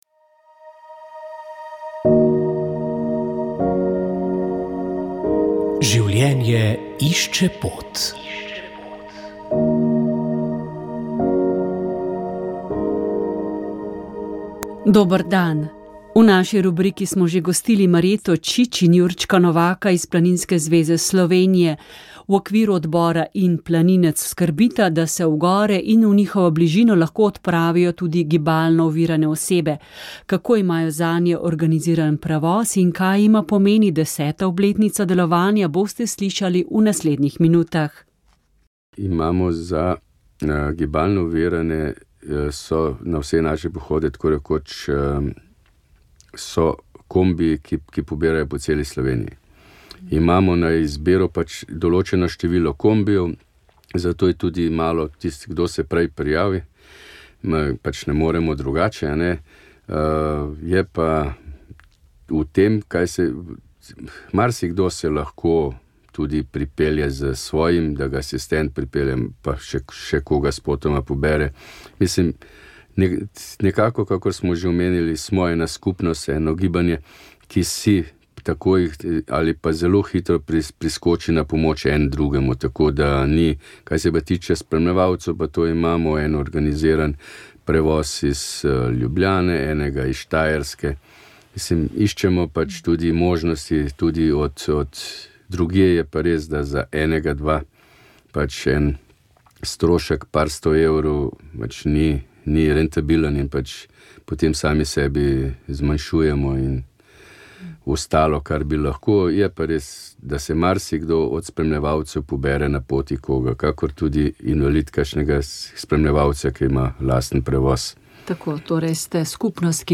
V oddaji Spoznanje več, predsodek manj je bil z nami dr. Žiga Turk. Poletno zatišje v politiki, jesenska vročica in napadi.